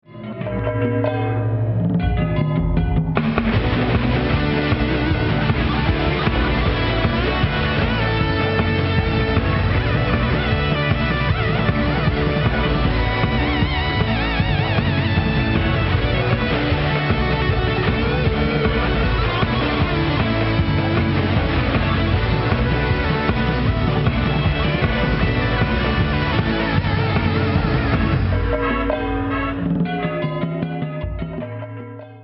rhythm programming, percussion
keyboards, bass, minimoog